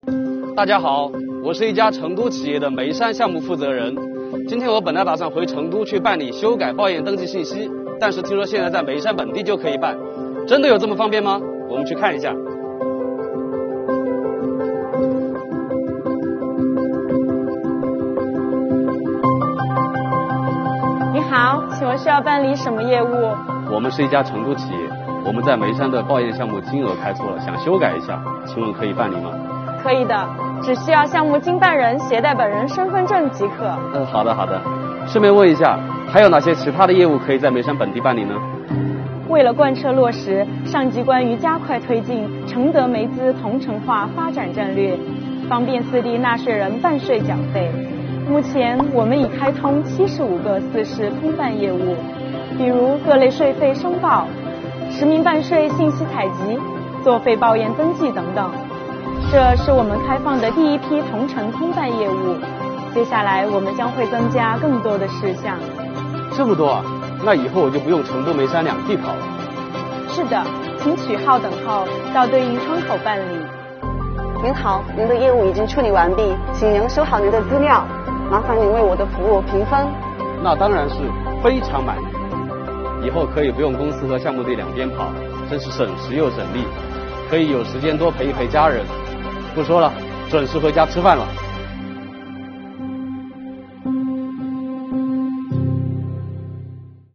川企负责人带您体验成德眉资“四市”通办↓